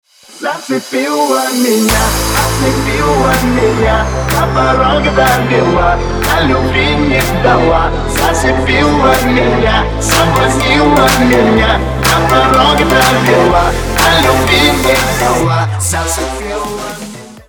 • Качество: 320, Stereo
мужской вокал
Club House